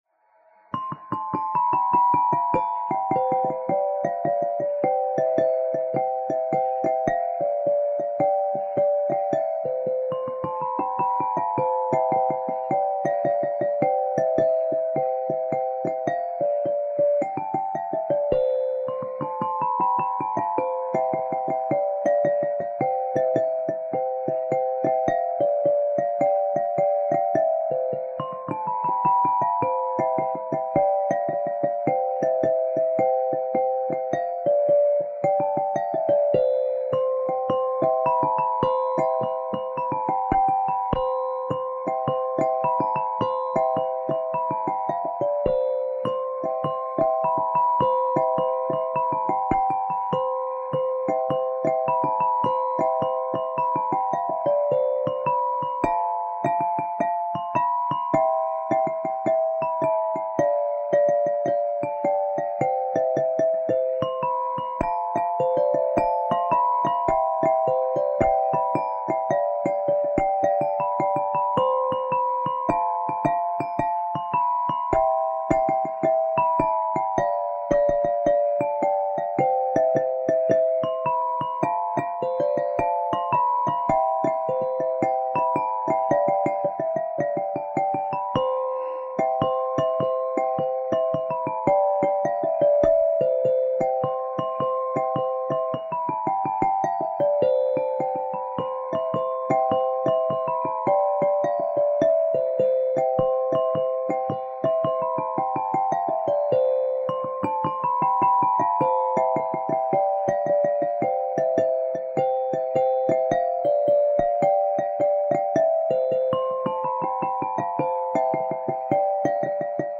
I lavori di elettrificazione del concerto di otto campane in Do3 – fuse da Angelo Ottolina di Bergamo nel 1950 – avvenuti nel 1998, avevano comportato l’eliminazione della tastiera manuale, calata e custodita in un angolo della casa parrocchiale, e l’eliminazione delle corde.
Suonata-Casnigo.mp3